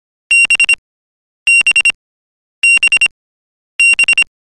Morse code online (Alphabet) - The letter B-B
• Category: Morse Code